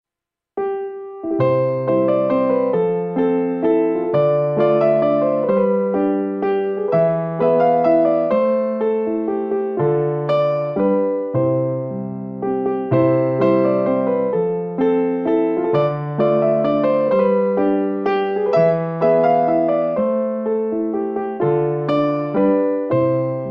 Kategori Jule